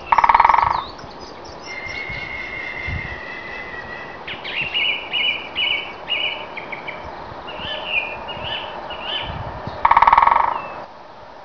The Great Spotted Woodpecker dominant sound is not vocal but drumming. Basically the bird hammers on a piece of wood that it finds resonates well.
Great Spotted Woodpecker Drumming (126K) was recorded from 30 metres away.
Actually we usually see him either on the grass probing for insects, or flying overhead making a very characteristic 'mocking' call which you can hear as part of the Great Spotted Woodpecker Drumming (126K) where the distant call immediately after the first drumming is the a fortuitous call of the Yaffle, affectionately known to us by this colloquial name.
woodpeck.wav